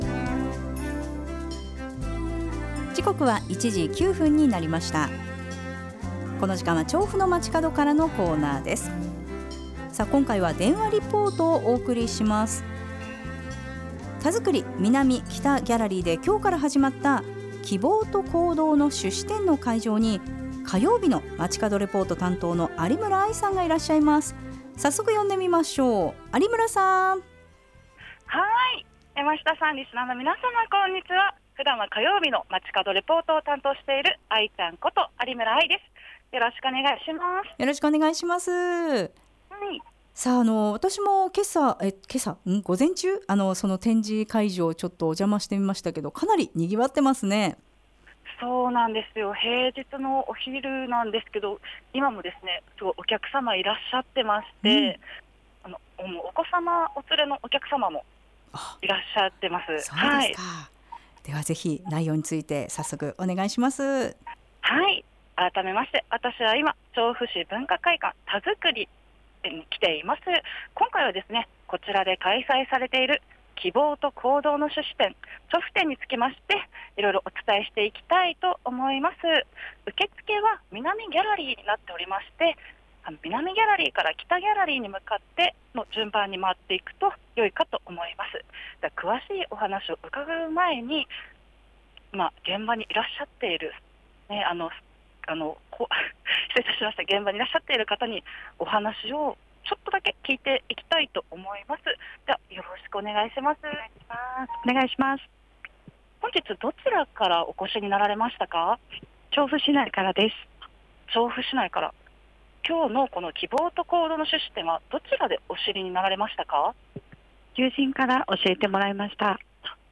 今回は1月22日(木)から文化会館たづくり南ギャラリー・北ギャラリーで開催されている「希望と行動の種子」展からお届けしました！